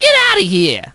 crow_get_hit_04.ogg